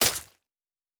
Stab 22_1.wav